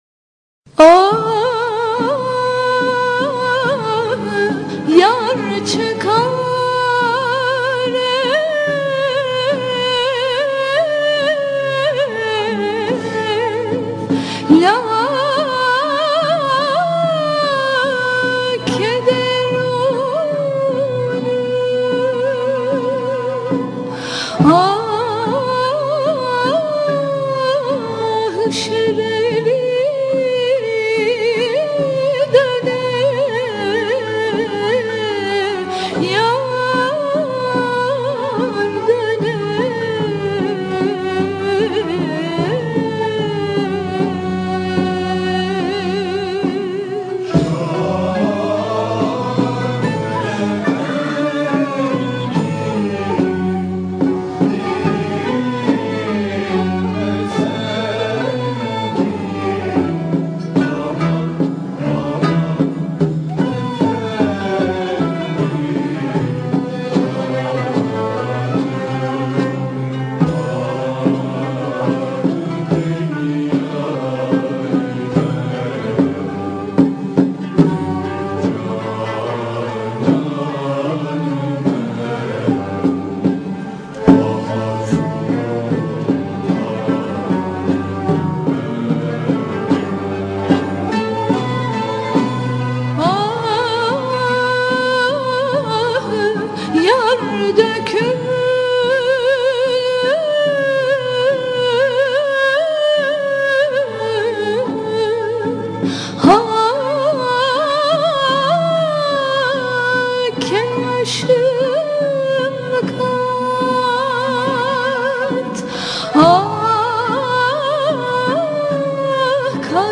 Usûl: Devr-i Kebir